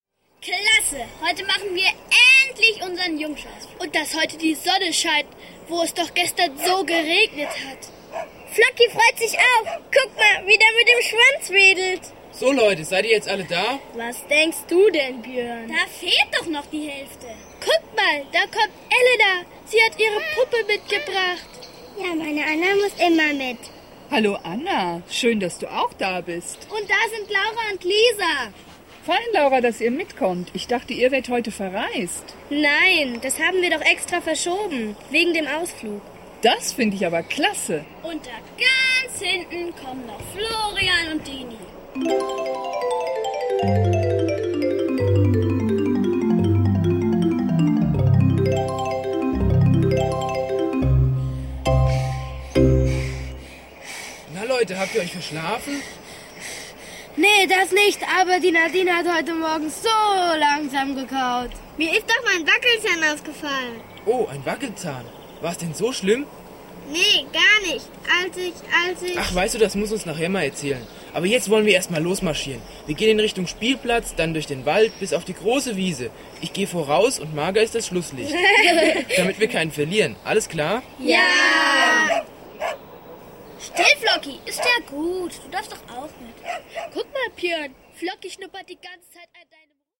Hörszene 1